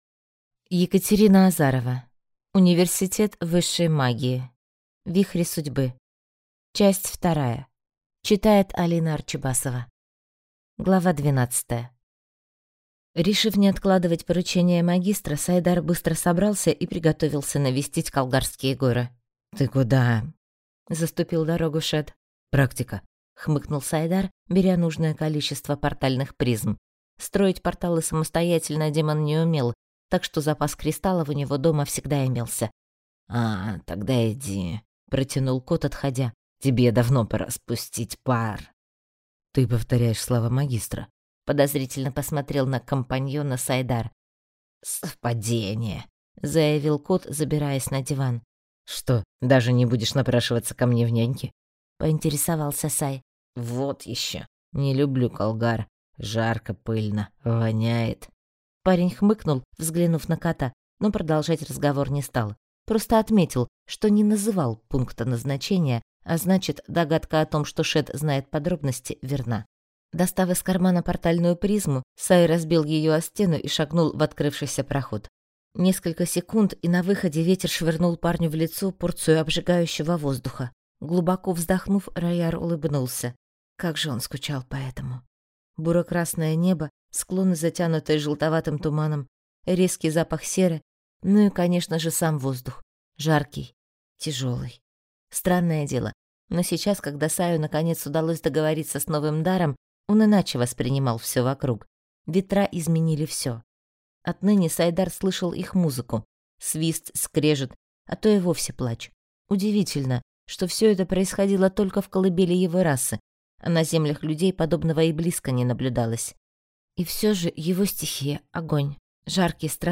Аудиокнига Университет высшей магии. Вихри судьбы. Часть 2 | Библиотека аудиокниг